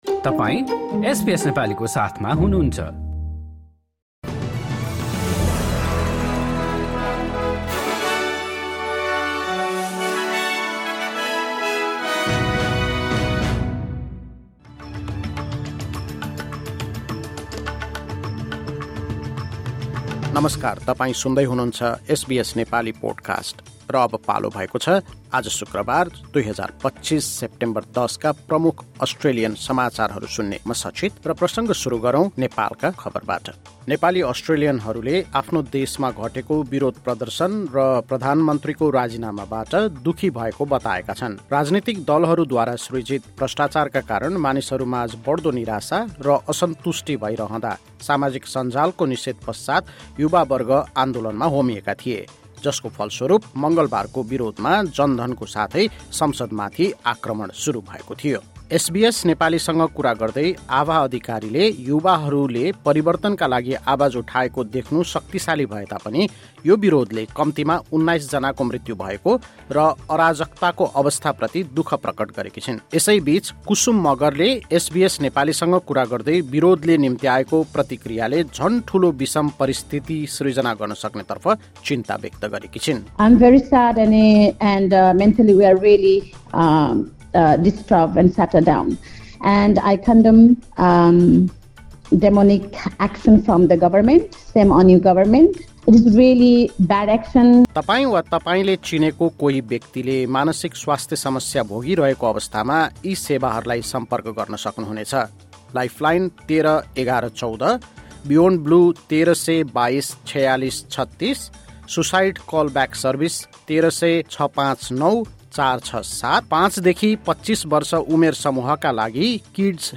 आजका प्रमुख अस्ट्रेलियन समाचार छोटकरीमा सुन्नुहोस्।